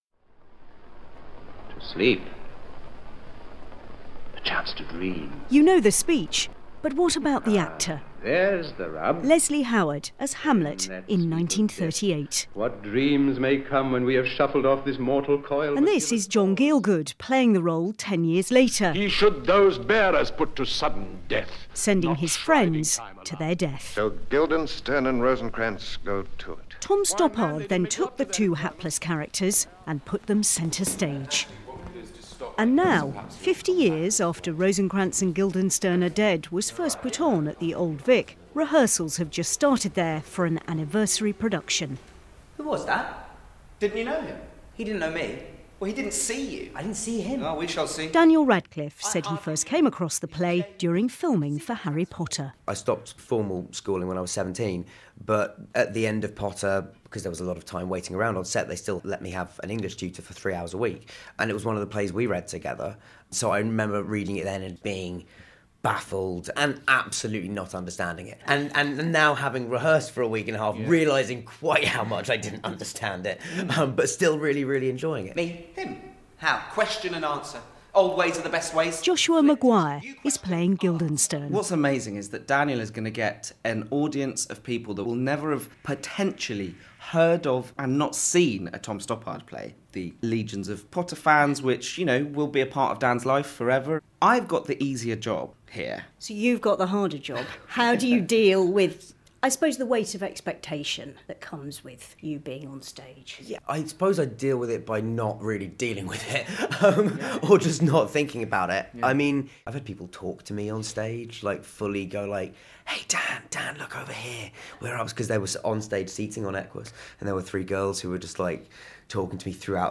report for BBC Radio 4's Today.